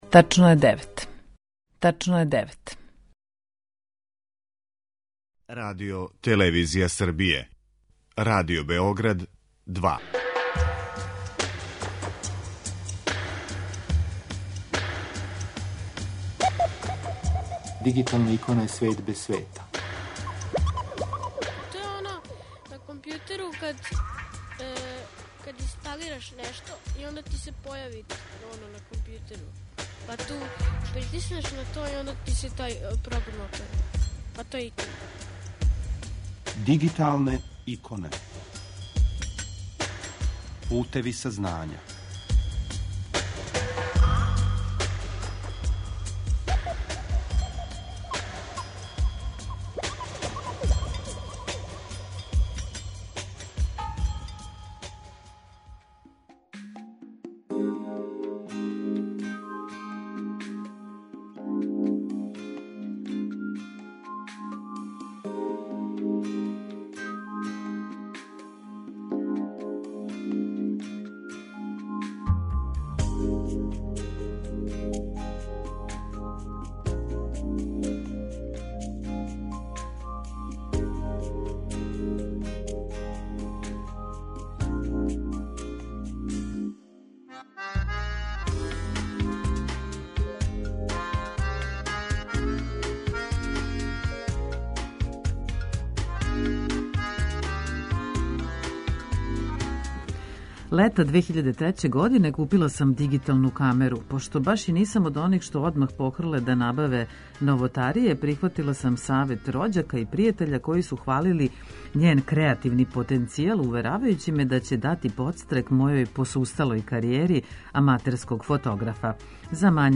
Извор: Радио Београд 2